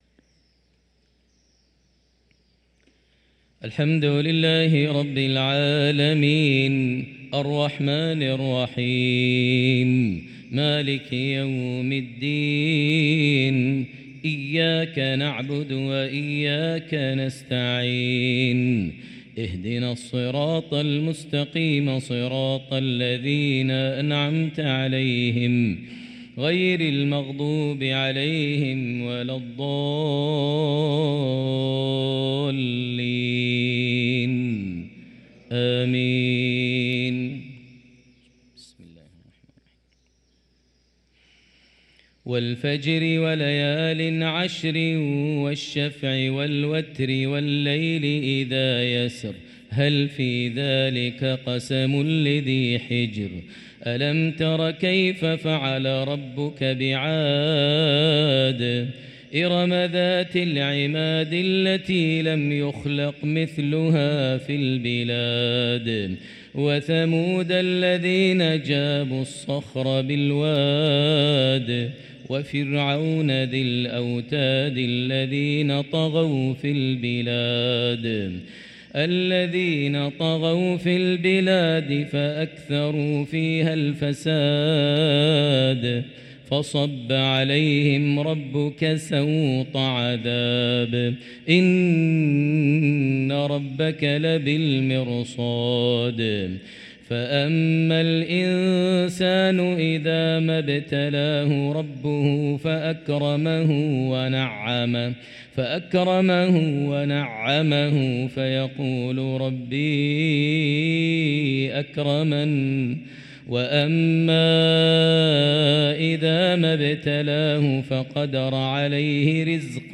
صلاة المغرب للقارئ ماهر المعيقلي 30 جمادي الآخر 1445 هـ
تِلَاوَات الْحَرَمَيْن .